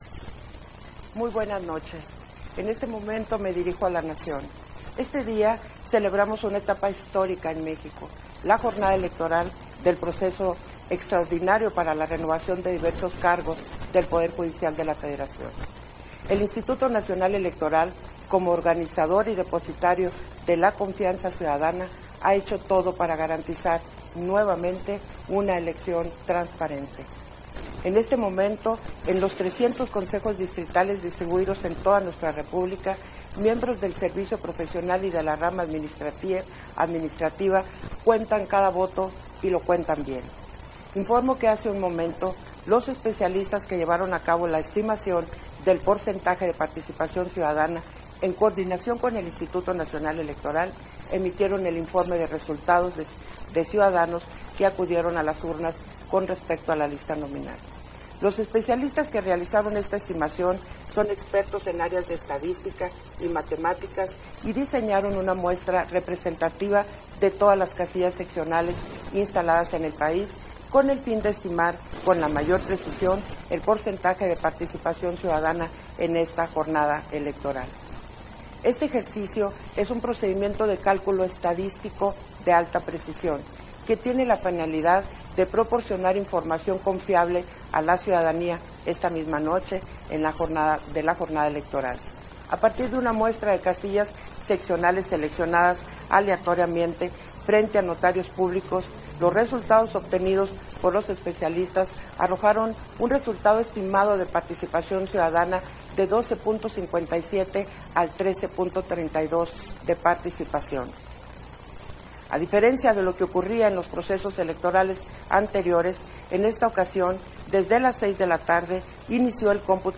Versión estenográfica del segundo mensaje en cadena nacional de la Consejera Presidenta, Guadalupe Taddei, con motivo de la jornada electoral de la primera elección del Poder Judicial